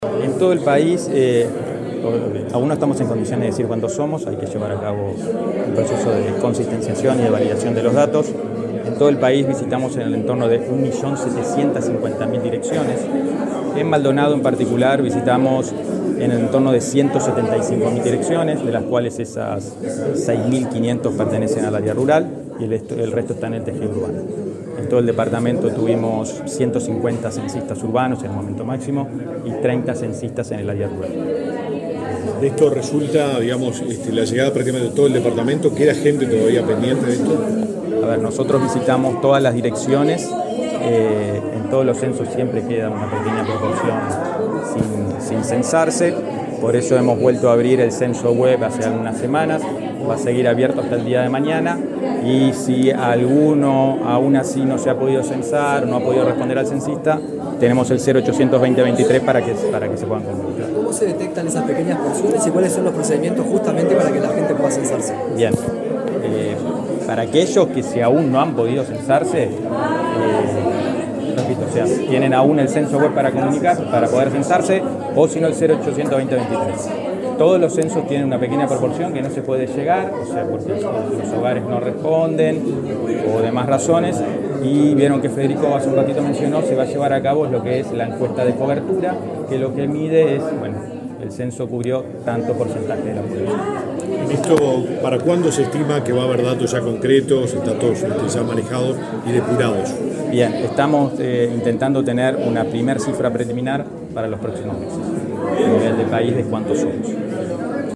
Declaraciones del director del Censo 2023, Leonardo Cuello
Declaraciones del director del Censo 2023, Leonardo Cuello 28/09/2023 Compartir Facebook X Copiar enlace WhatsApp LinkedIn El director del Censo 2023 del Instituto Nacional de Estadística (INE), Leonardo Cuello, informó en Maldonado acerca del cierre de ese trabajo en el departamento. Luego dialogó con la prensa.